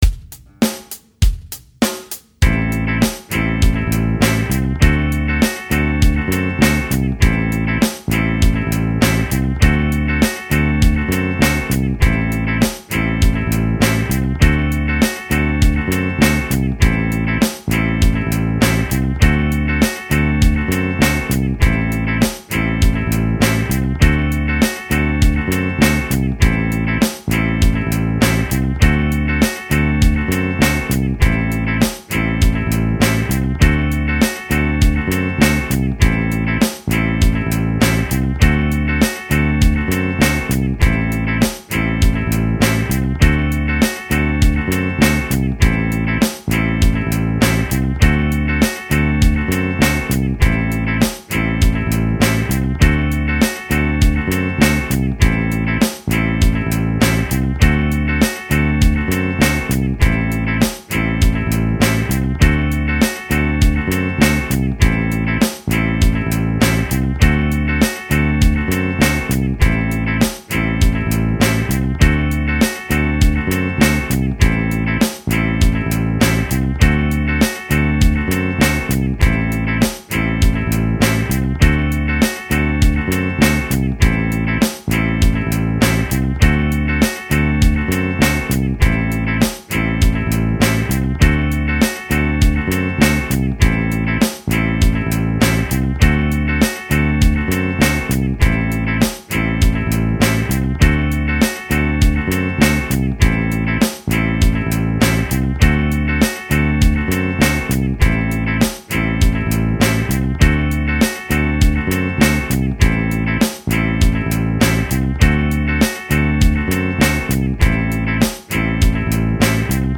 dorian_a_backing.mp3